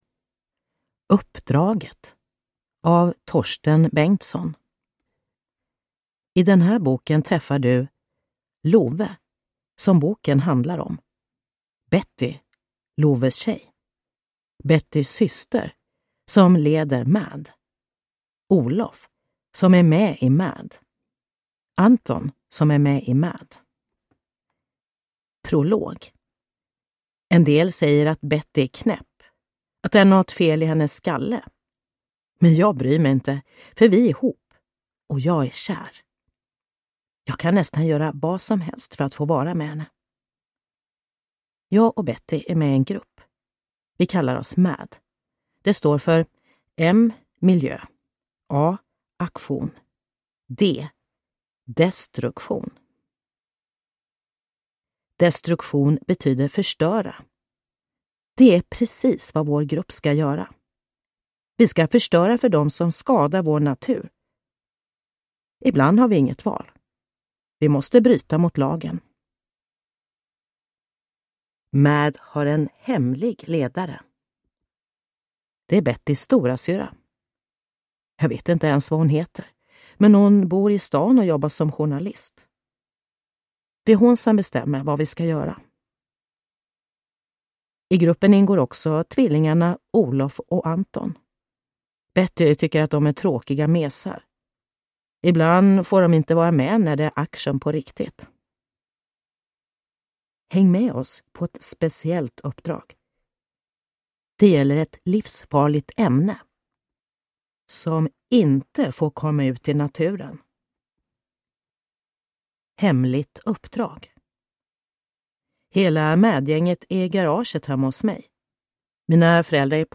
Uppdraget (ljudbok) av Torsten Bengtsson | Bokon